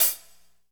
Index of /90_sSampleCDs/Northstar - Drumscapes Roland/DRM_Pop_Country/HAT_P_C Hats x
HAT P C L09R.wav